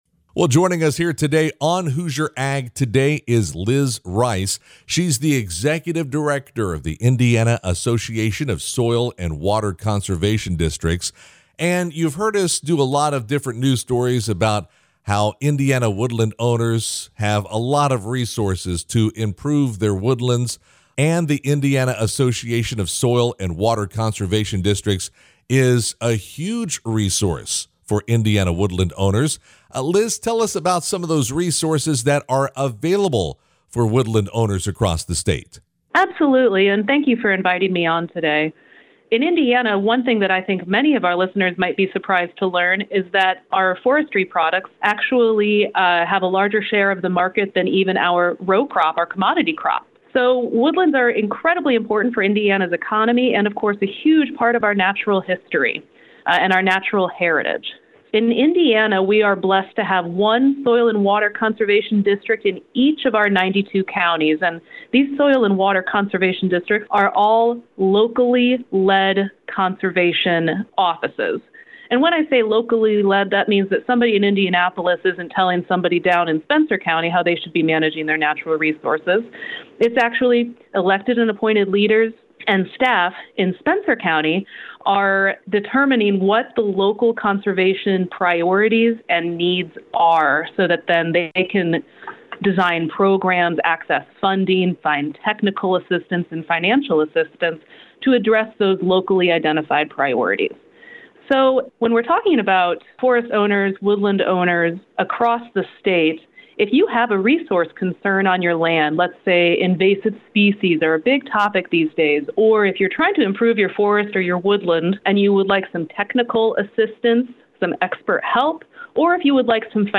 full conversation